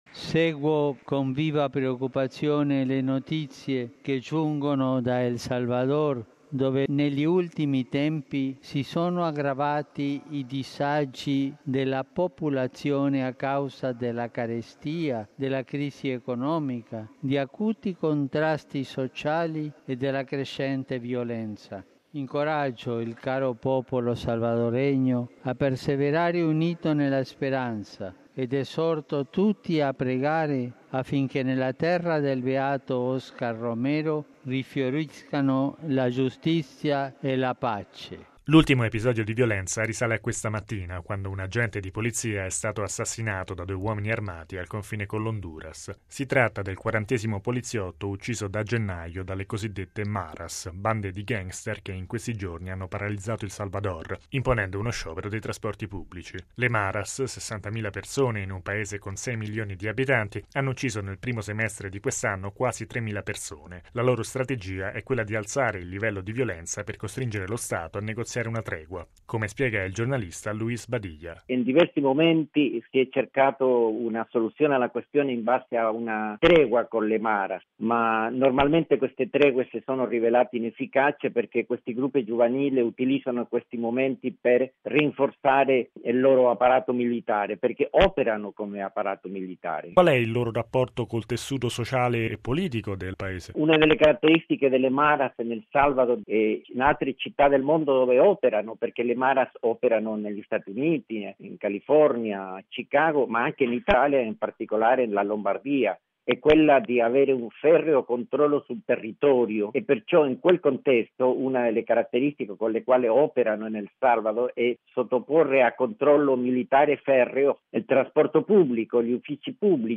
Al termine dell’Angelus il Papa ha invitato i fedeli a pregare per la popolazione del Salvador.